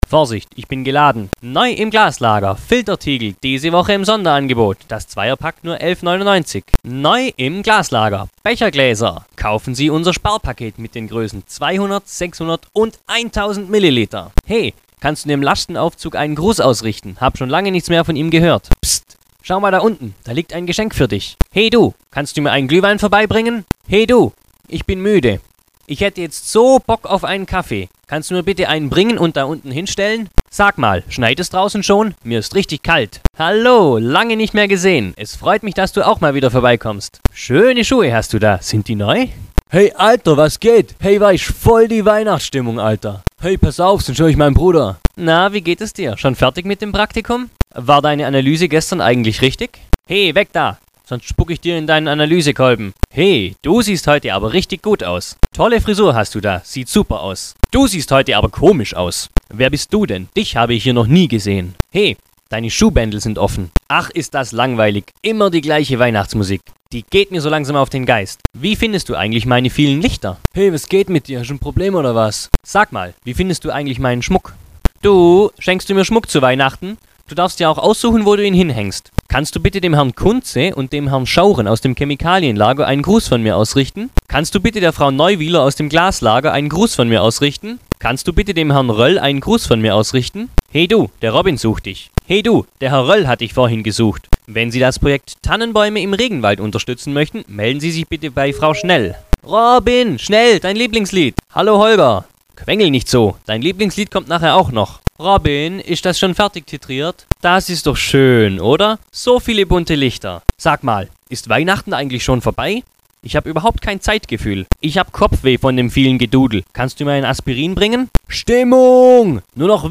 Kategorie 4: Sprüche, die beim Berühren des Baums abgespielt werden: